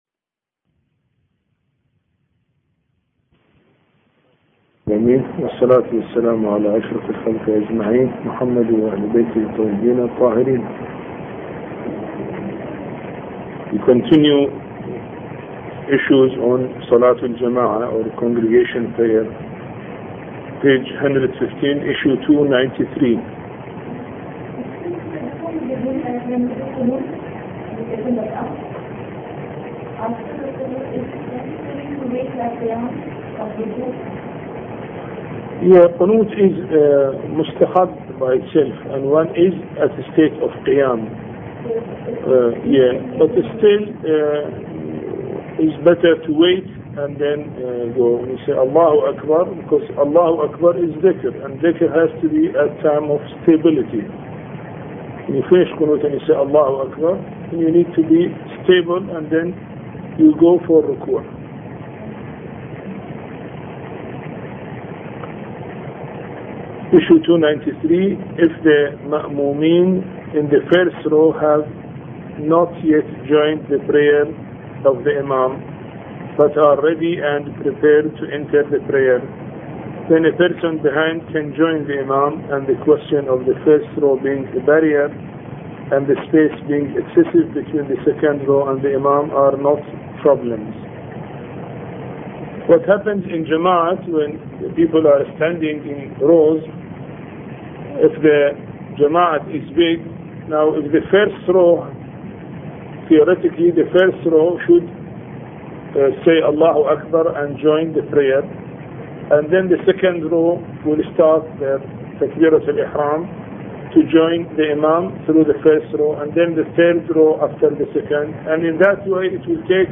A Course on Fiqh Lecture 17